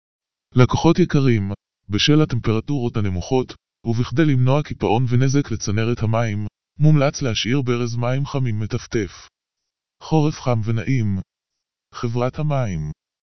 המרת הודעות SMS להודעות קוליות
הודעת דוגמא 4 (קול אשה)